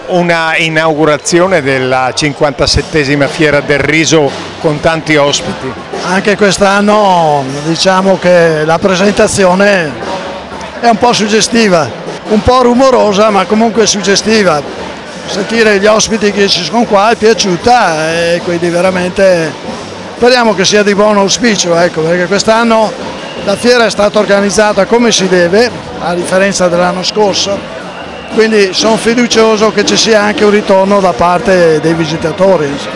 all’evento inaugurale:
Luigi Mirandola, Sindaco di Isola della Scala